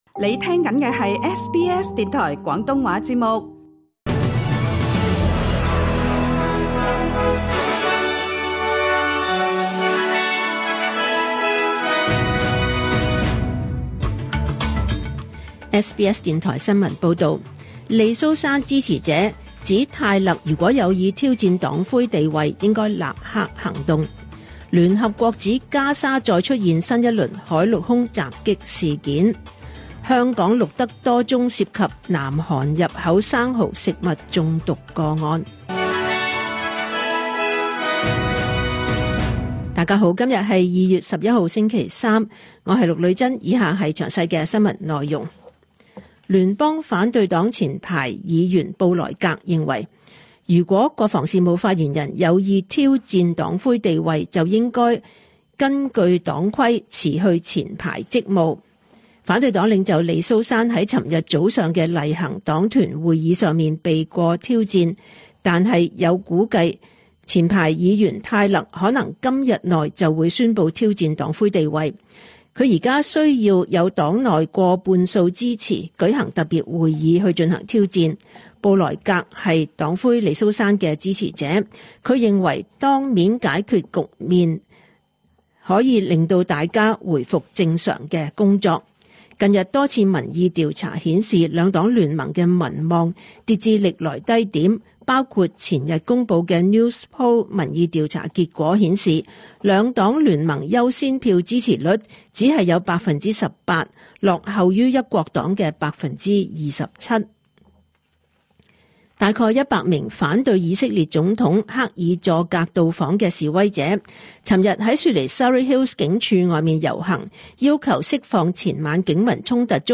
2026 年 2 月 11 日 SBS 廣東話節目詳盡早晨新聞報道。